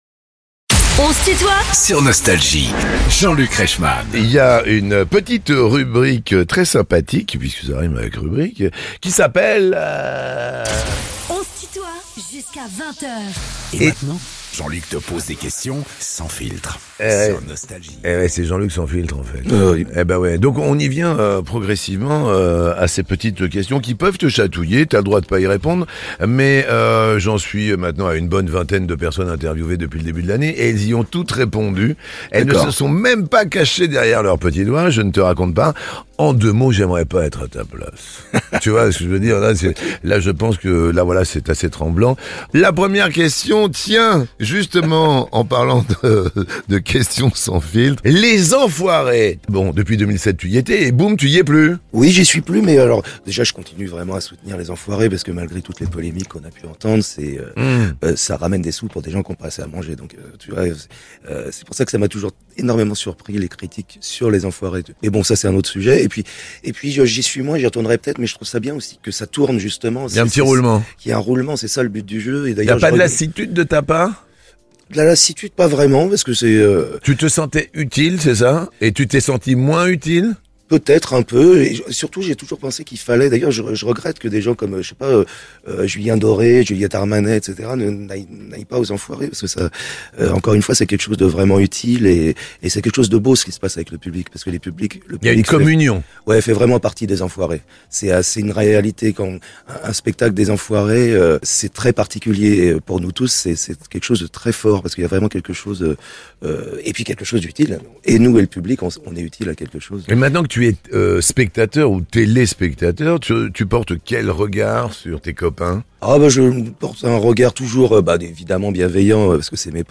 Benabar est l'invité de "On se tutoie ?..." avec Jean-Luc Reichmann